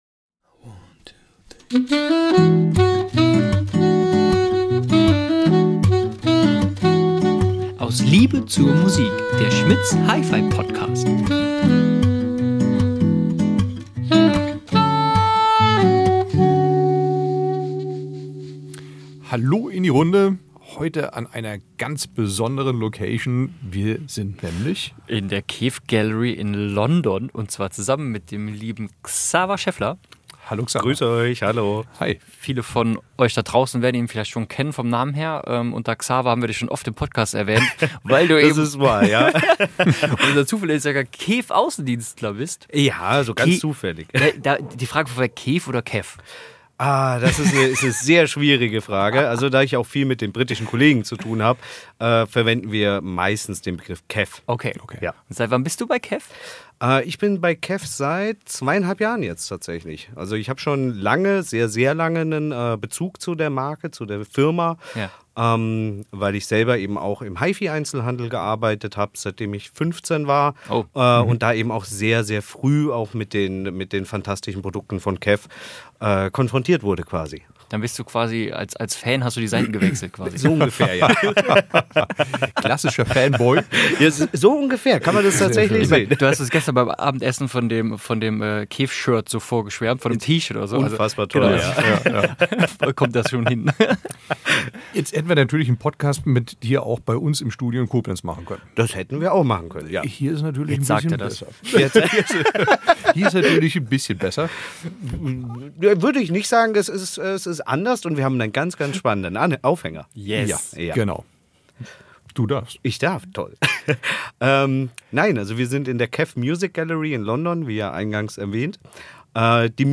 119. KEF Music Gallery London: Ein Gespräch